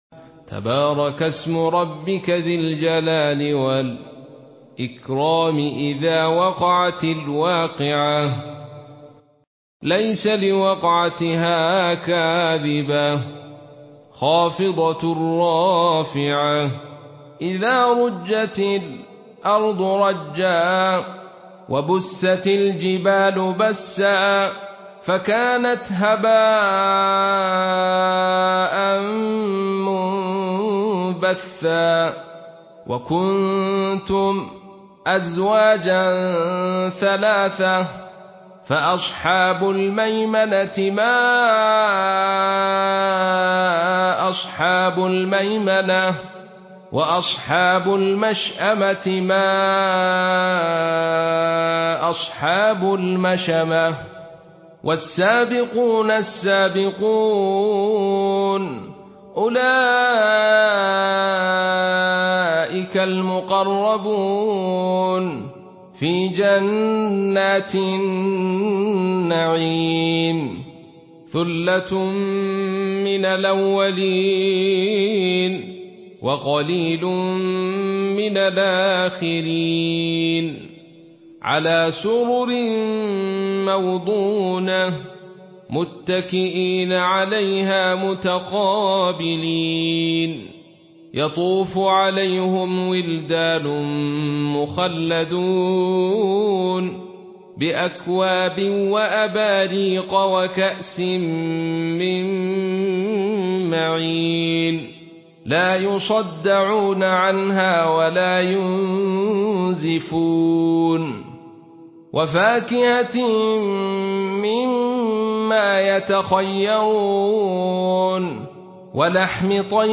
Surah Vakia MP3 by Abdul Rashid Sufi in Khalaf An Hamza narration.